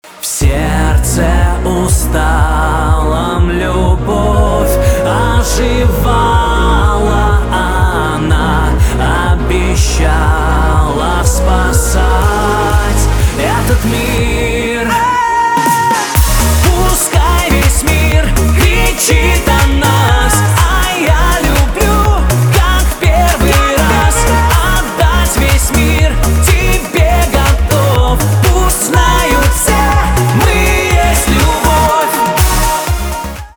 поп
битовые , чувственные , романтические